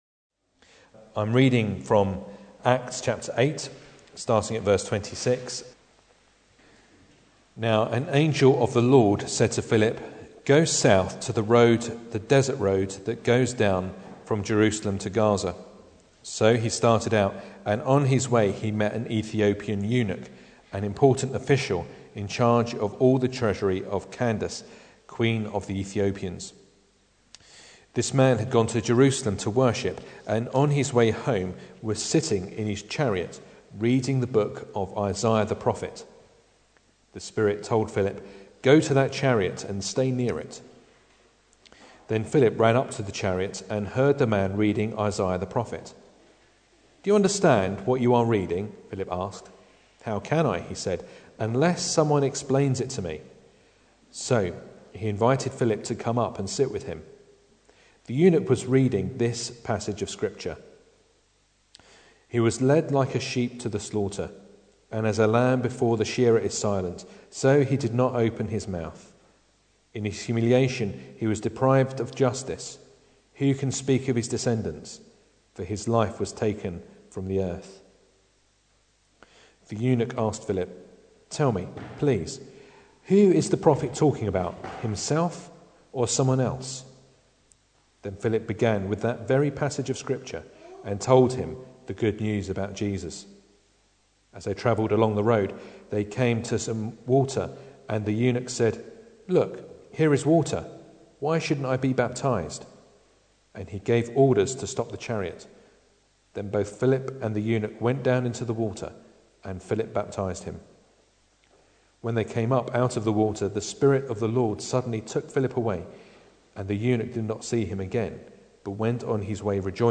Acts Passage: Acts 8:26-40 Service Type: Sunday Evening Bible Text